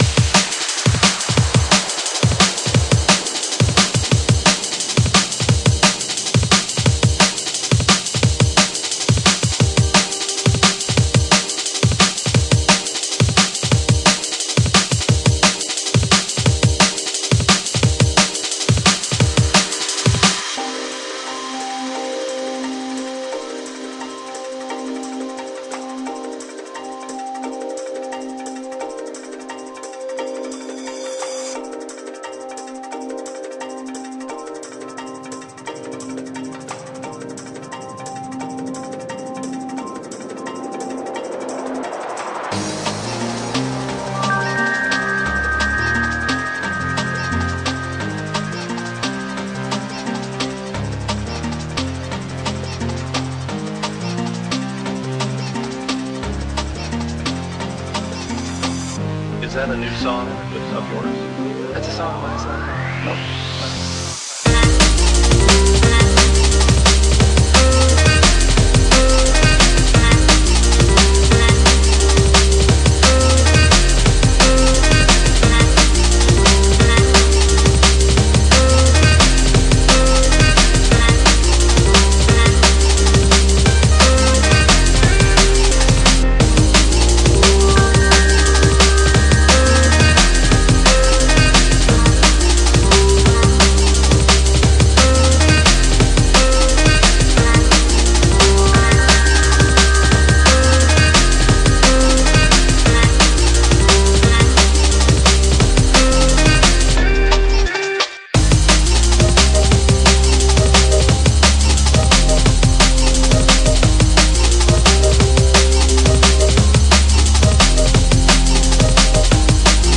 relatively chill drum ‘n’ bass song